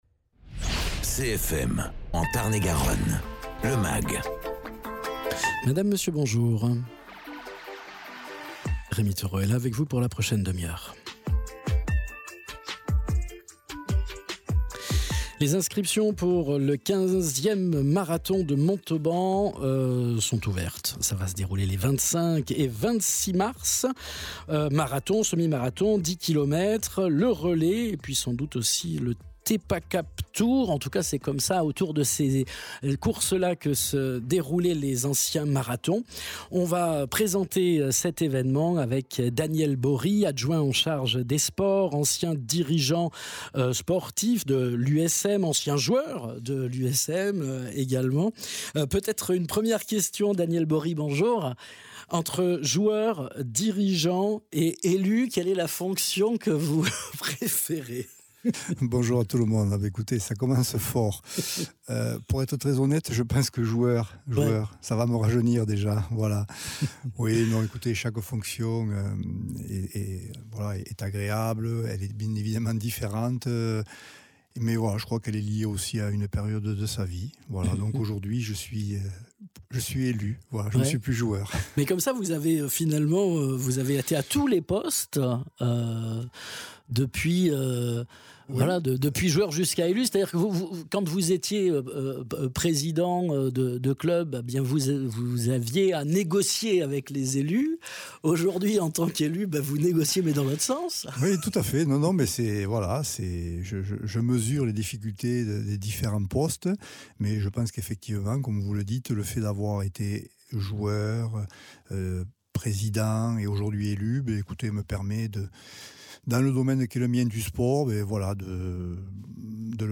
Invité(s) : Daniel Bory adjoint aux sports à la ville de Montauban